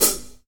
• 1970s Foot Cymbal A Key 14.wav
Royality free hi-hat tuned to the A note. Loudest frequency: 8123Hz